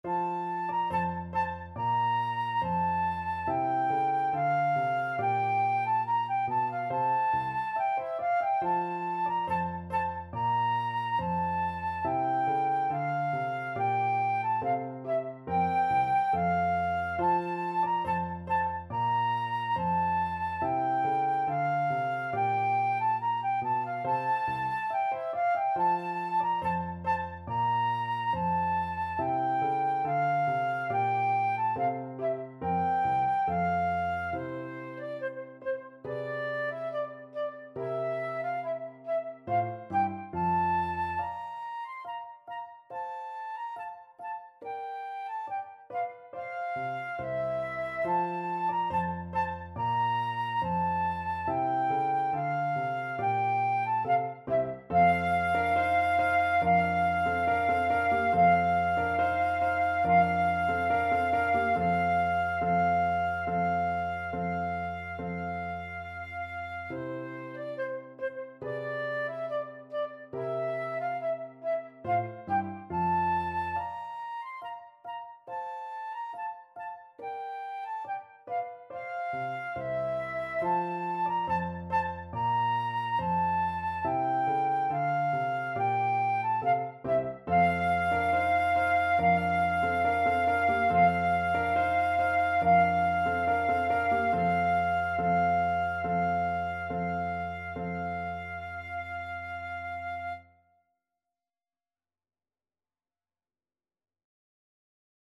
Flute
F major (Sounding Pitch) (View more F major Music for Flute )
2/4 (View more 2/4 Music)
Classical (View more Classical Flute Music)
haydn_anthony_FL.mp3